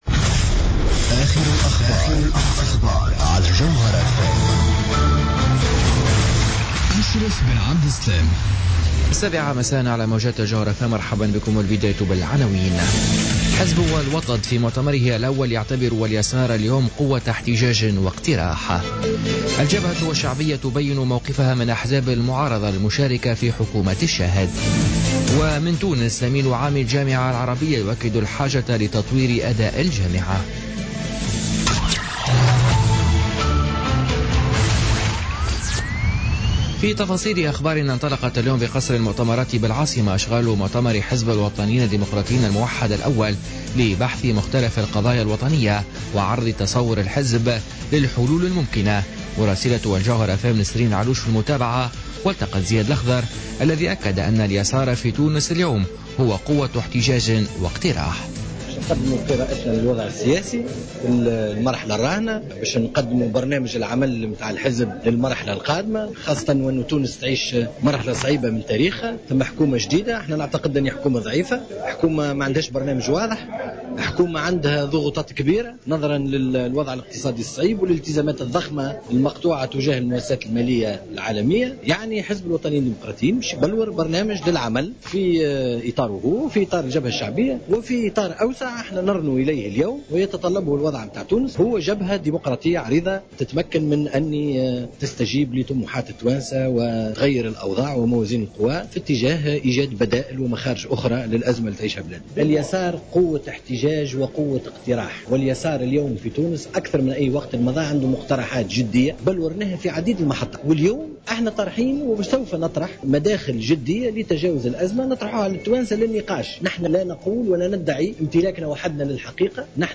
نشرة أخبار السابعة مساء ليوم الجمعة 2 سبتمبر 2016